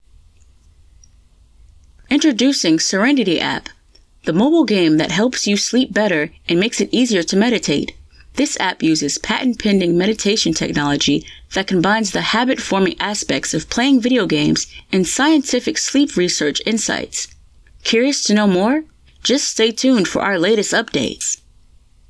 Promo
Broadcast-ready home studio.
ExplainerSample.wav